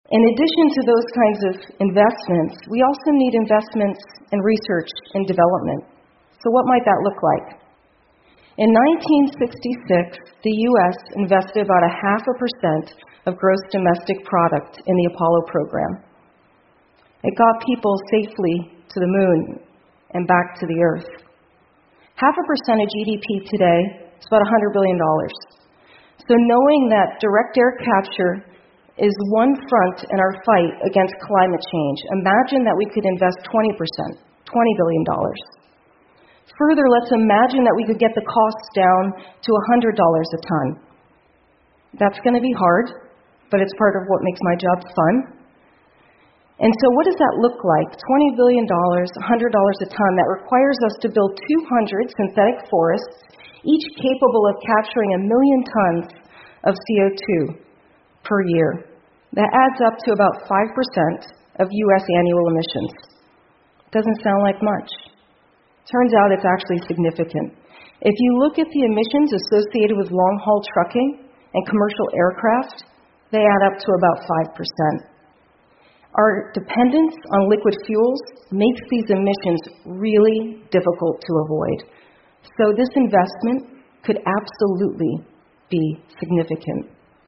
TED演讲:从大气中移除二氧化碳的新方法(7) 听力文件下载—在线英语听力室